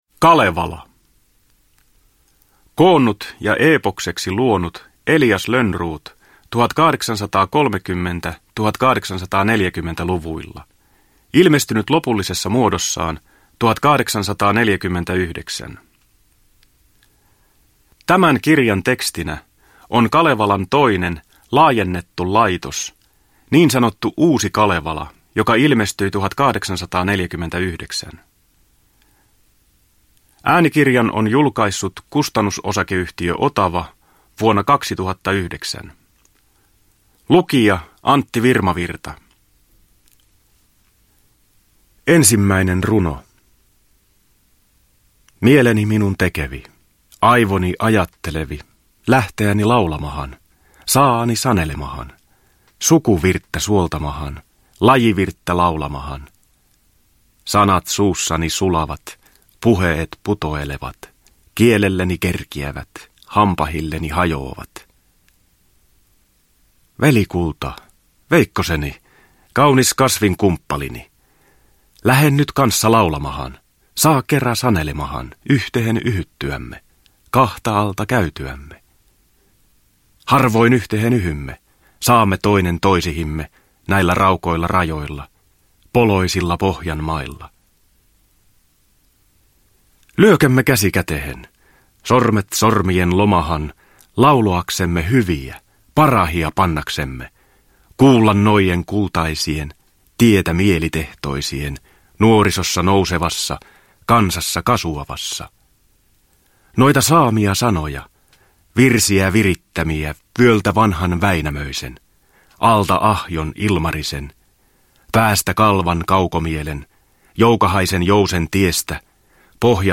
Kalevala – Ljudbok – Laddas ner